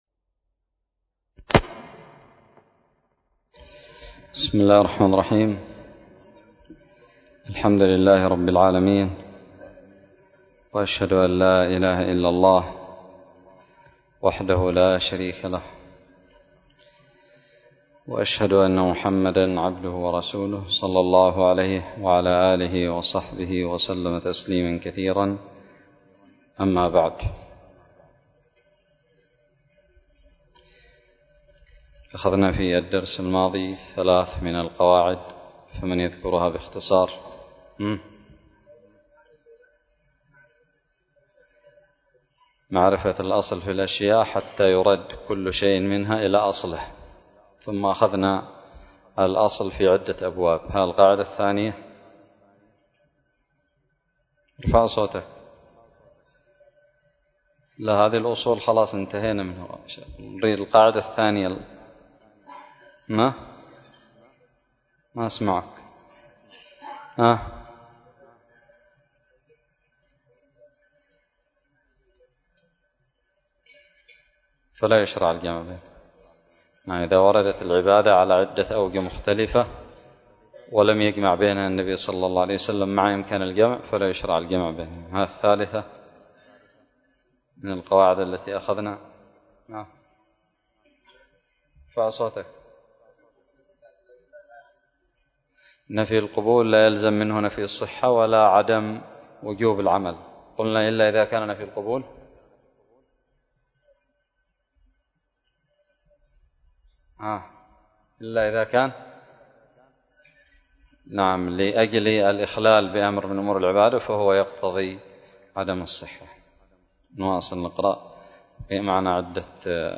الدرس الثالث عشر من شرح كتاب المنتقى للمجد ابن تيمية
ألقيت بدار الحديث السلفية للعلوم الشرعية بالضالع